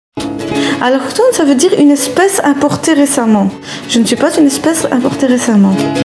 Extrait du film Place de Belgique, projeté au Magasin de Mots le 7 avril 2011.